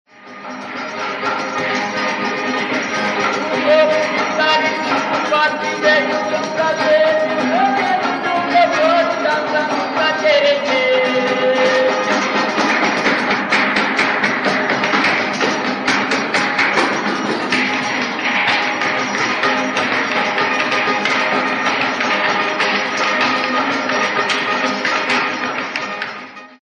Quando ocorre a presença feminina, duas fileiras, uma de homens outra de mulheres, se defrontam para apresentar cantos, sapateados, palmas e troca de lugar com dançadores fronteiros, breves saltos e rápida formação em círculo. O acompanhamento é feito especialmente por violas, em geral duas. Os violeiros, os únicos que cantam, também fazem parte da dança e dirigem a coreografia, que se parece com a dos cocos nordestinos. O cateretê é dançado nos estados de São Paulo, Rio de Janeiro, Minas Gerais, Mato Grosso e Goiás.
autor: Ciranda de Paraty., data: 1975